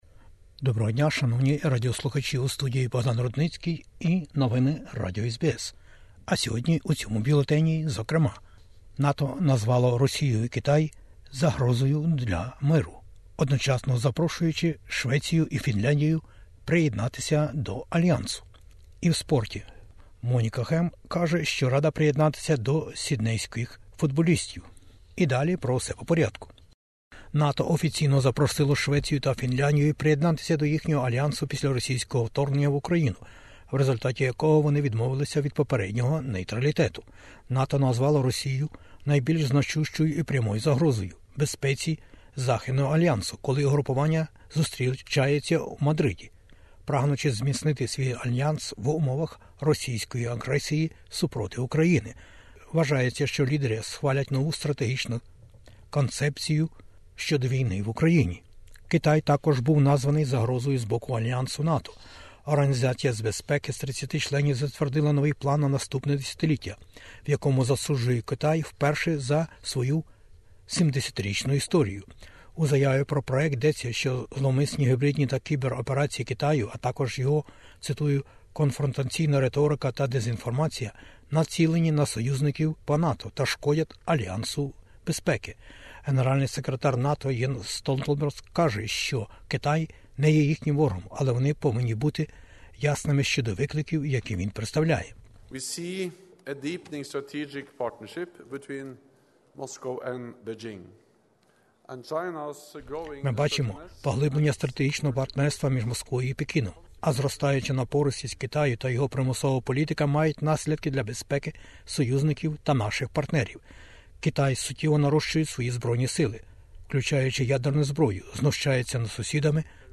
Бюлетень SBS новин українською. Австралія - НАТО: Прем'єр-міністр Австралії у Мадриді.